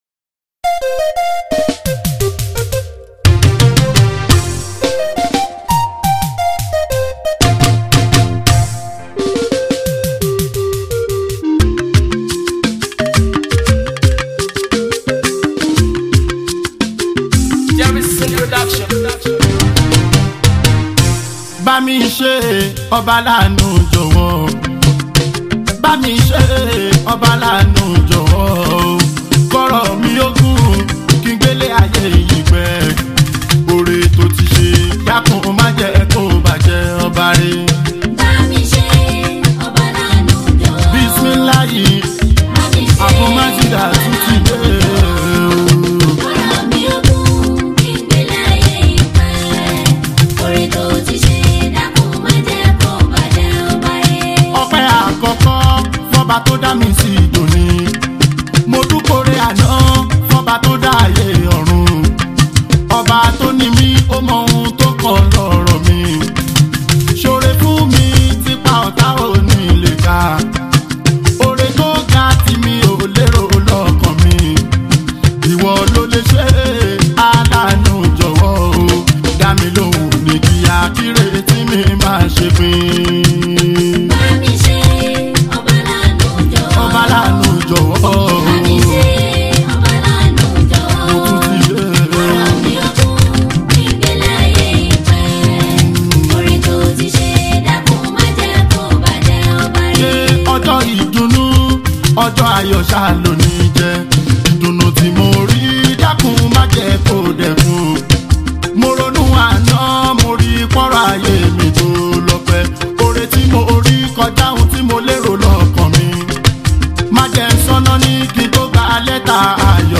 Yoruba Islamic Music 0
Nigerian Yoruba Fuji track
especially if you’re a lover of Yoruba Fuji Sounds